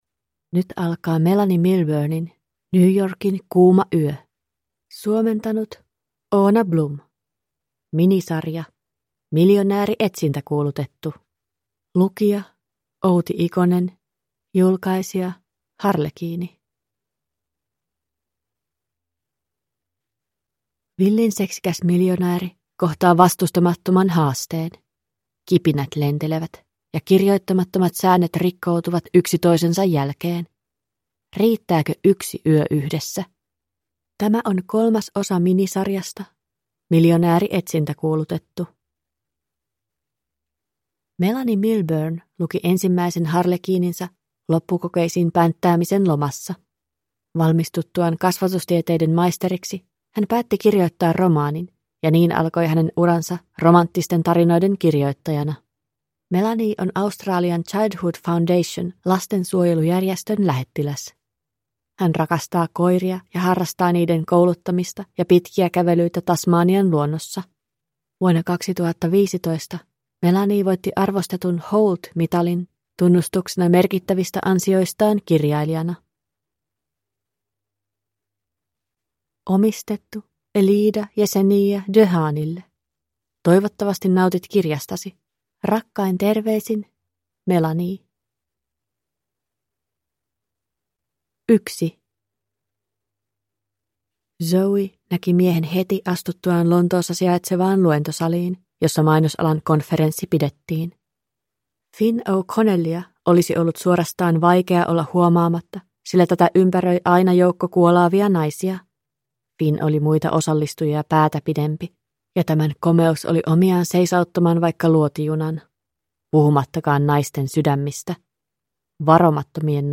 New Yorkin kuuma yö (ljudbok) av Melanie Milburne